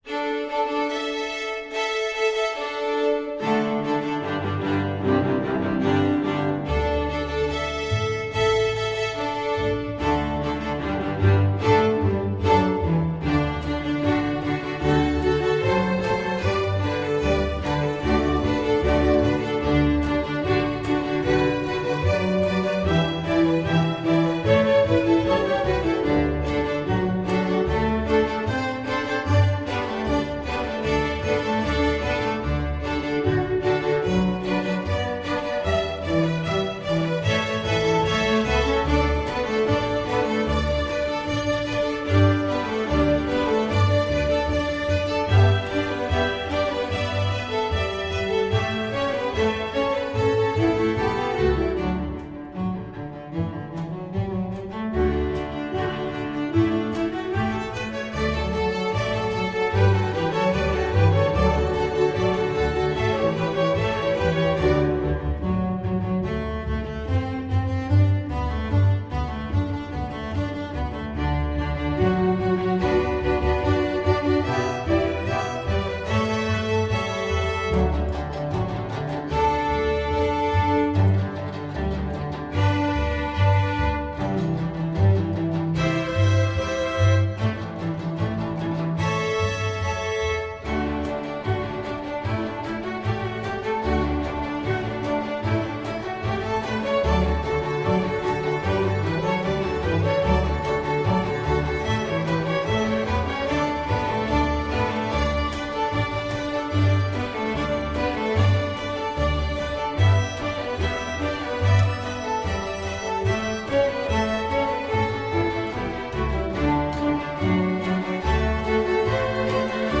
Voicing: String Orchestra C